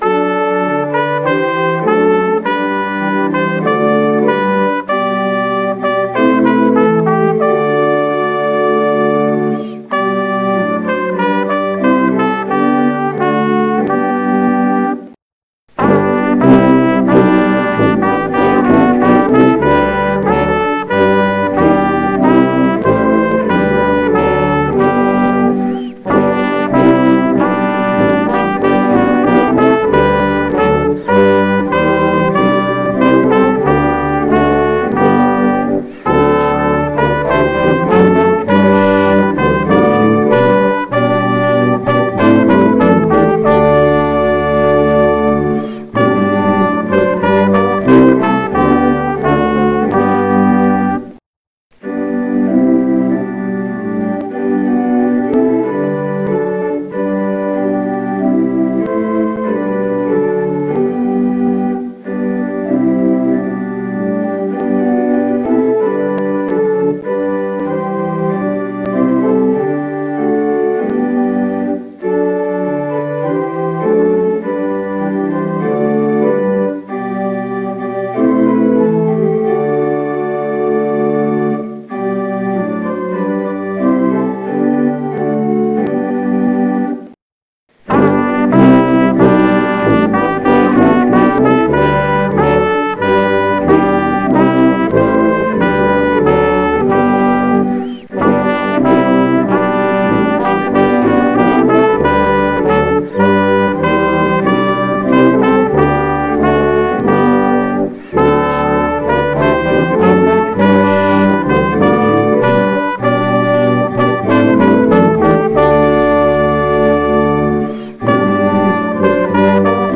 Music on the Sunday of Pentecost
organist
elw-618-organbrass.mp3